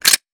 weapon_foley_pickup_20.wav